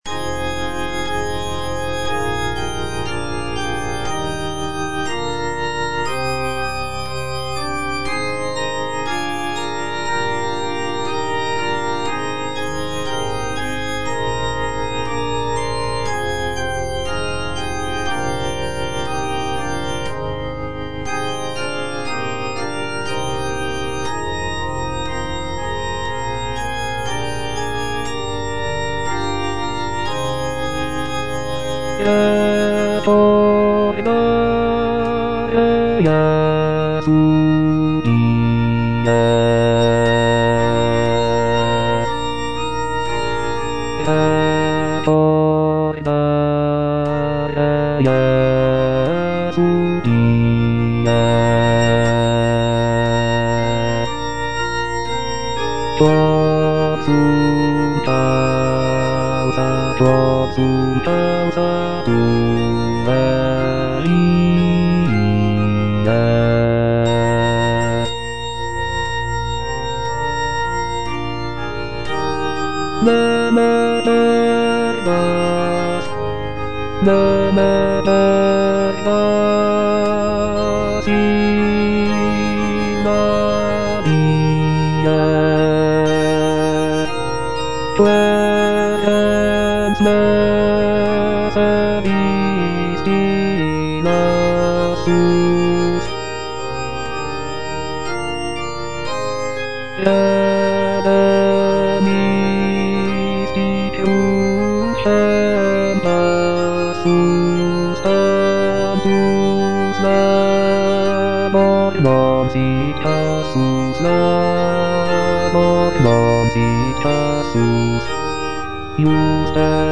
Bass (Voice with metronome) Ads stop
is a sacred choral work rooted in his Christian faith.